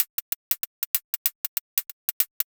Index of /musicradar/ultimate-hihat-samples/95bpm
UHH_ElectroHatC_95-01.wav